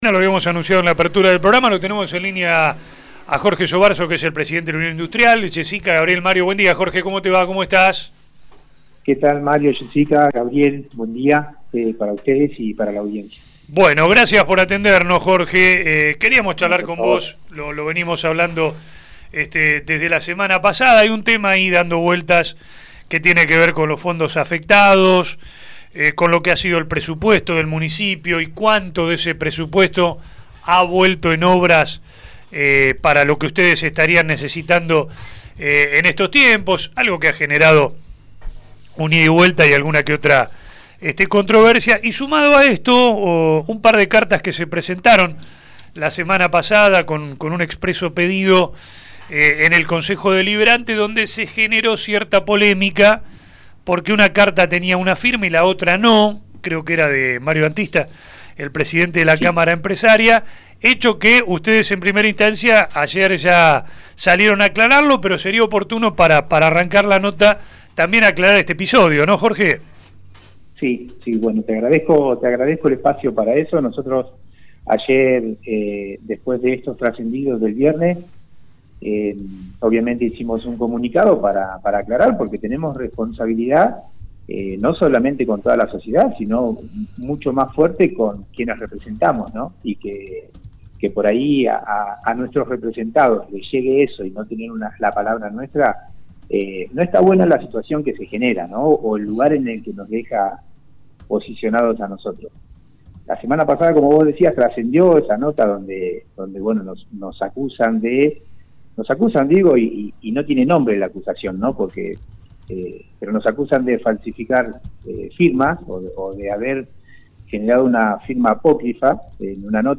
📌 En la entrevista se abordan tanto el contenido del proyecto como la reciente controversia vinculada al uso de firmas digitales.